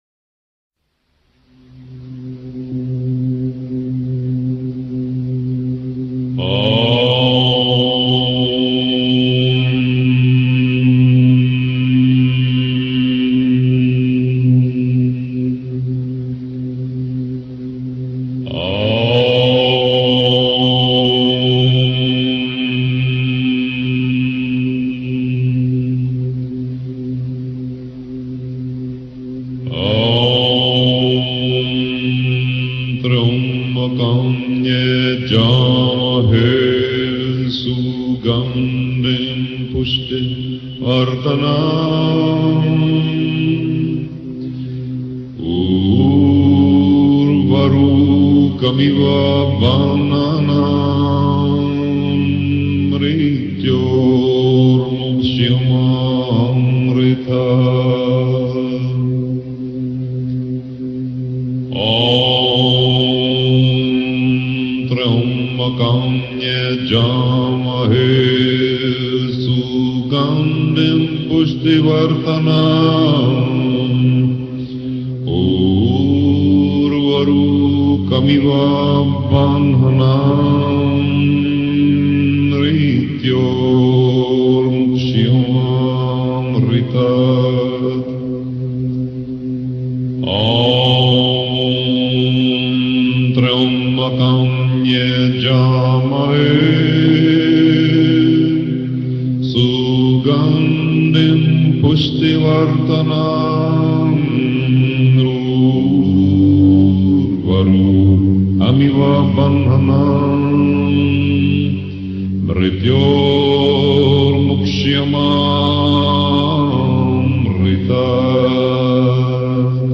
Ce chant tibétain active la guérison intérieure
CHANTS TIBÉTAINS
Chant-tibetain-pour-la-guerison-interieure.mp3